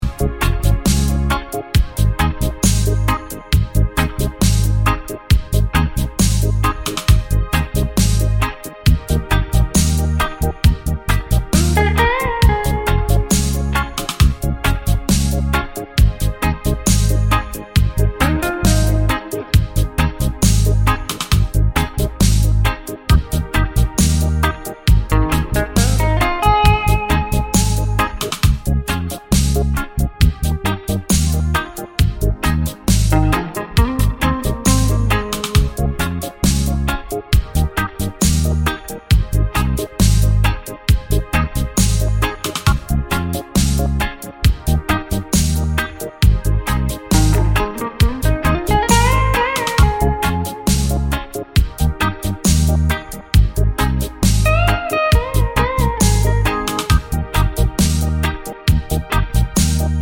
no Backing Vocals Reggae 4:13 Buy £1.50